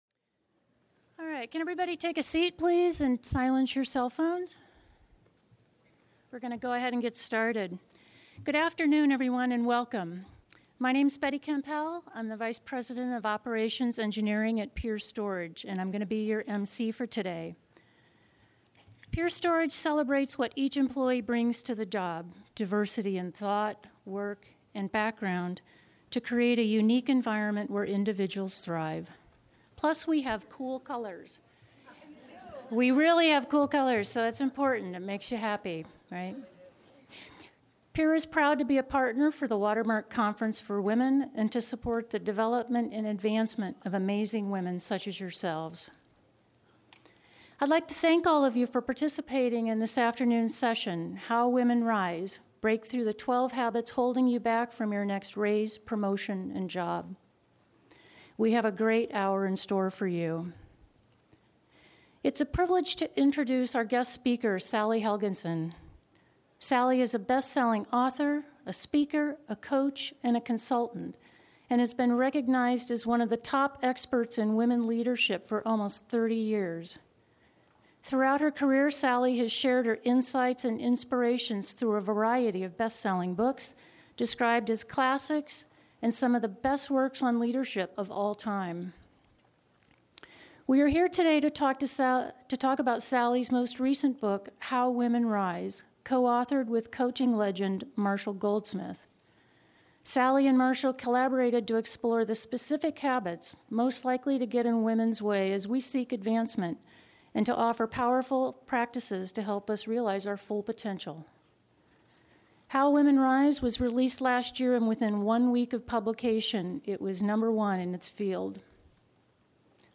Click Play below to listen to this session from the 2019 conference.